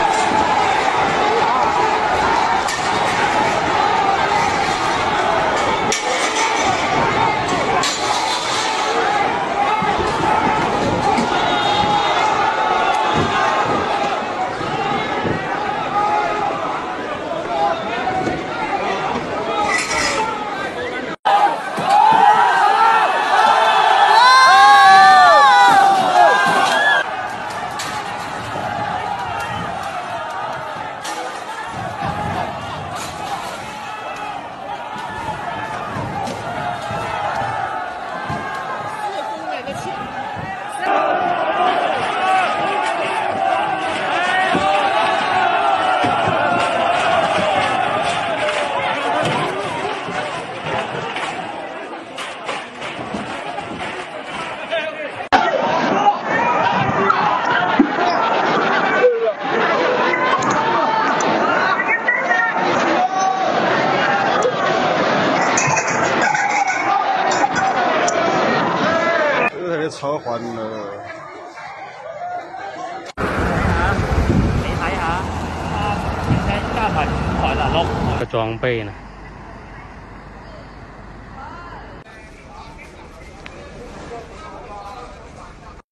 Chinesen sollen angeblich ja sehr genügsam sein, doch die harte und unverhältnismässige Lockdownpolitik scheint nun auch in China bei der Bevölkerung der Geduldsfaden zu reissen. Hier Lockdownprotest in Guangdong, China...